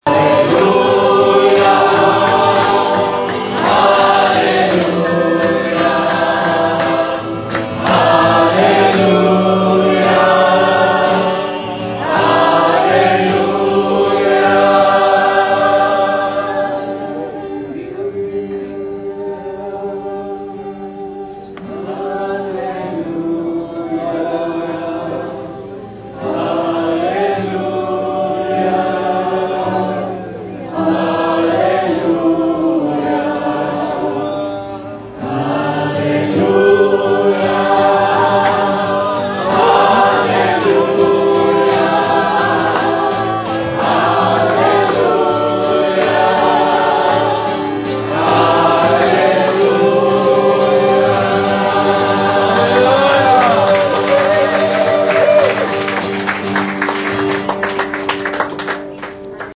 Naharia: May 21-23, 2019.
Included below is a sound clip of one worship song sung by the congregation, understandable in Hebrew, Arabic, English, plus many more.
“Hallelujah” as sung by believers congregated in Naharia at the Kingdom First conference.